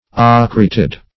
Ochreate \O"chre*ate\, Ochreated \O"chre*a`ted\, a.